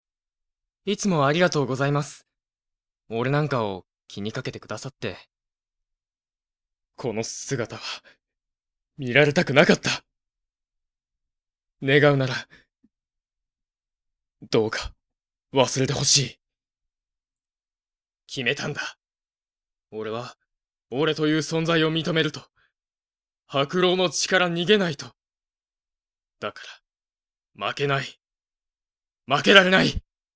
演じていただきました！
性別：男性
線の細い、少し頼りない印象の青年。